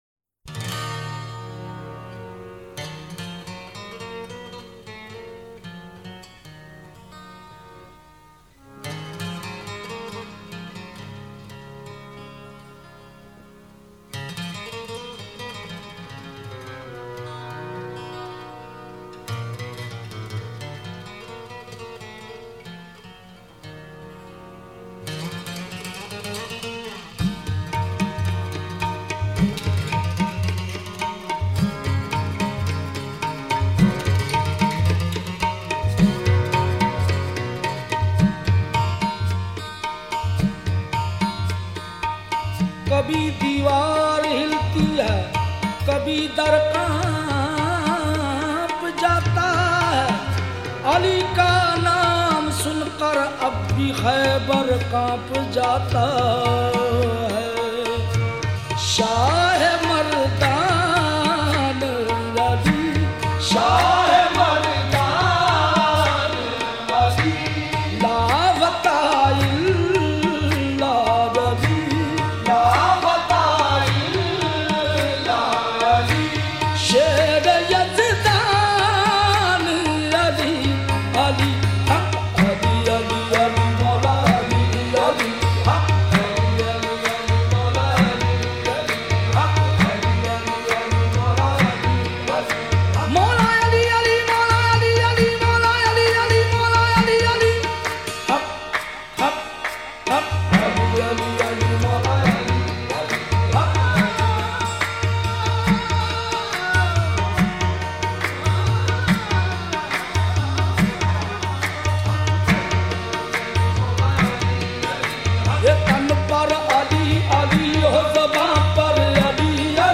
sufiana kalam